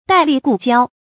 戴笠故交 注音： ㄉㄞˋ ㄌㄧˋ ㄍㄨˋ ㄐㄧㄠ 讀音讀法： 意思解釋： 謂貧賤之交。